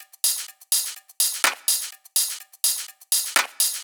Live Percussion A 19.wav